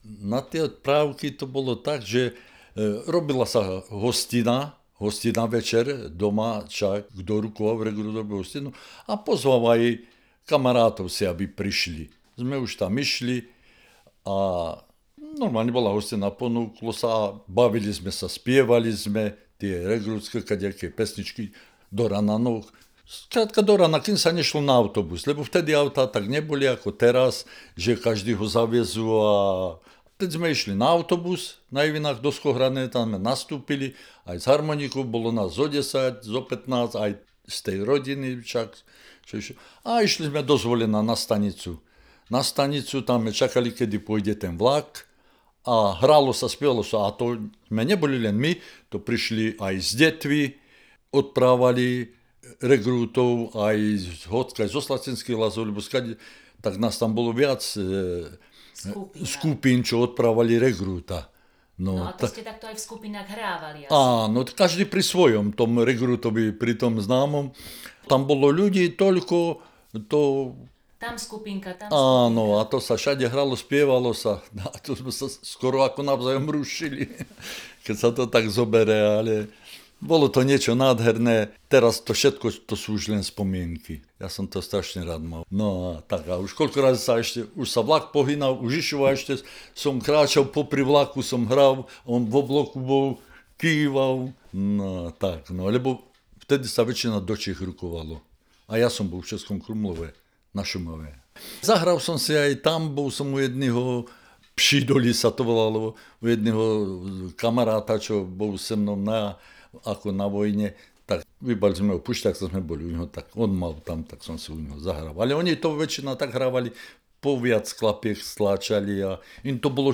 Hra na heligónke z Dúbrav 001-04